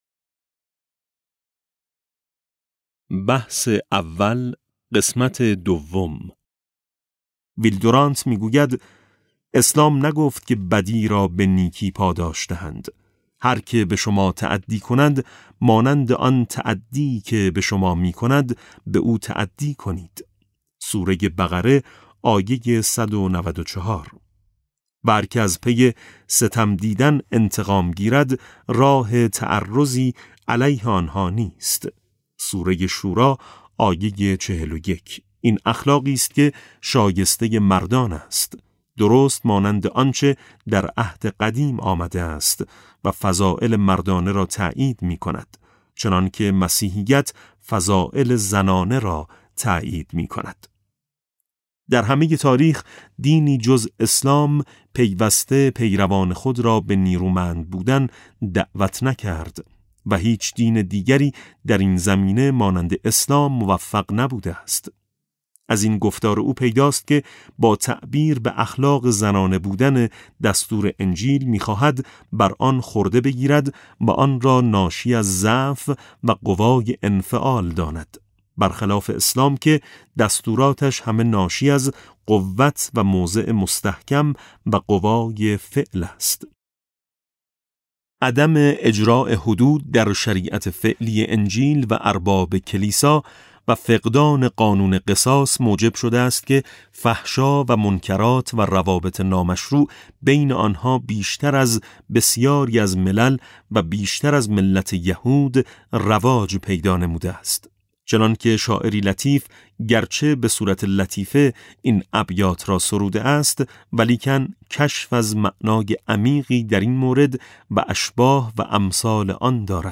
کتاب صوتی نور ملکوت قرآن - ج1 ( 19 تعداد فایل ها ) | علامه طهرانی | مکتب وحی